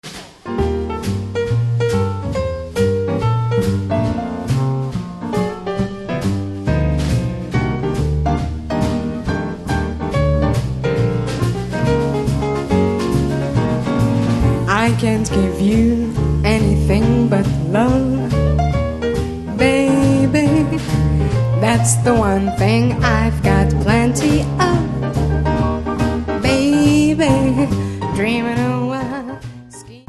Gesang
Piano
Kontrabass
Schlagzeug